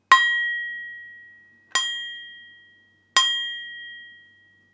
ding metal plate sound effect free sound royalty free Sound Effects